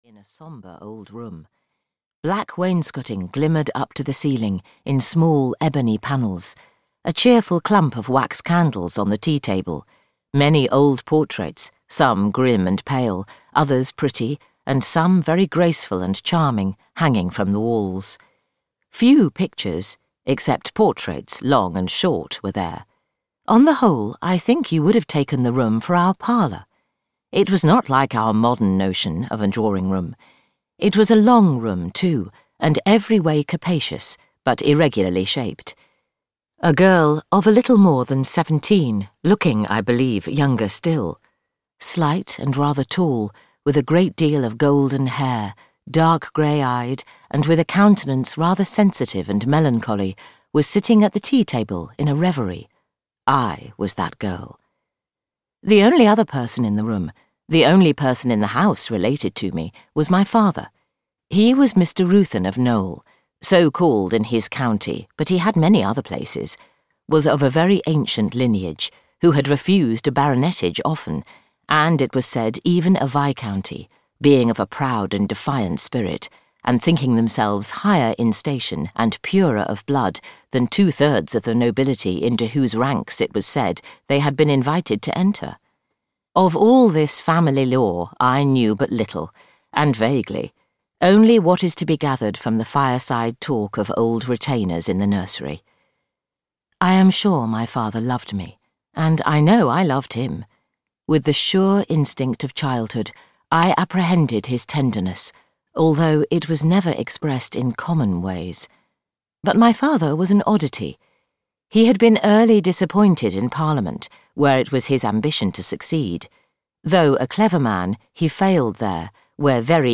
Uncle Silas (EN) audiokniha
Ukázka z knihy